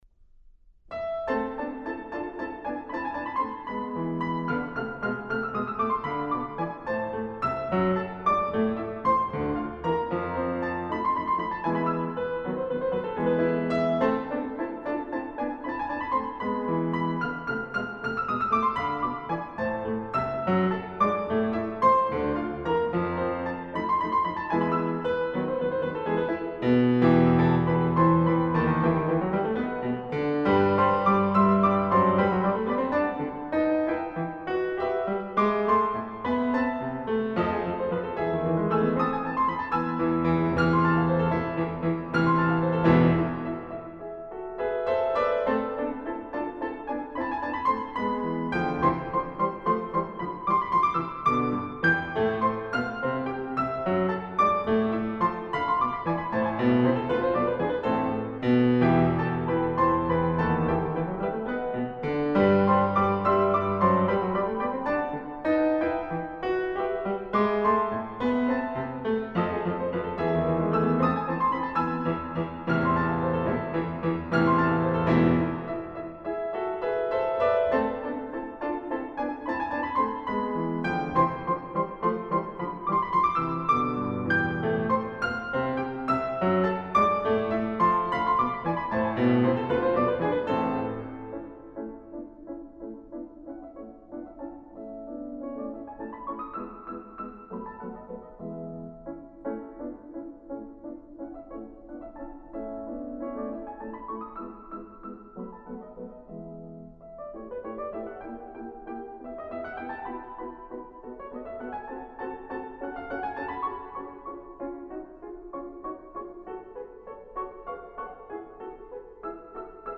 匈牙利钢琴家。